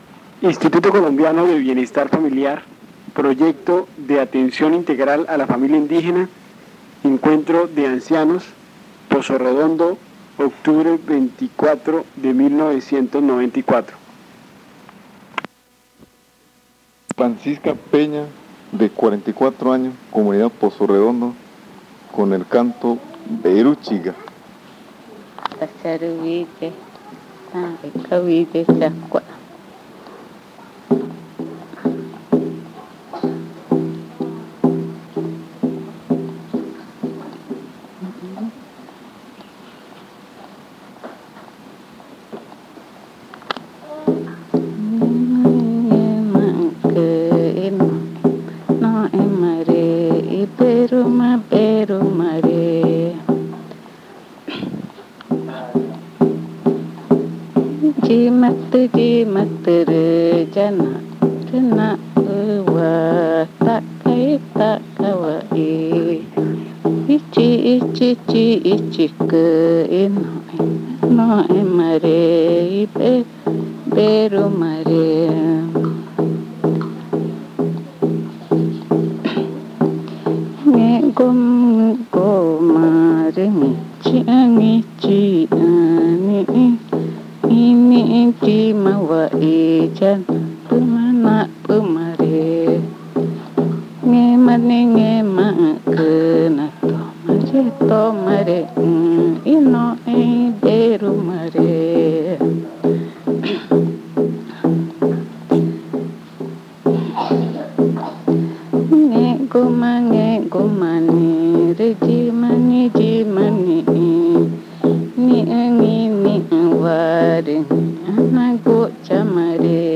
Canciones del ritual de la pubertad magütá
durante el Encuentro de Ancianos Recuperación de Tradición Oral dado en la Comunidad Indígena de Pozo Redondo el 24 de octubre de 1994. Se grabaron un total de tres casetes, de los cuales este es el segundo volumen.